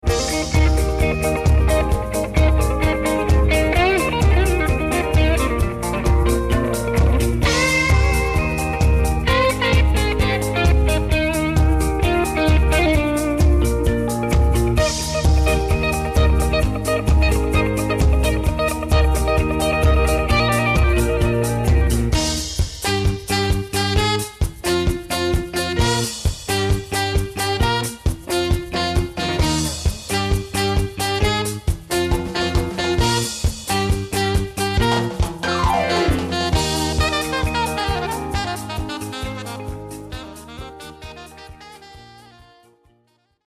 einer Mischung aus Latin, Soul, Reggae und Jazz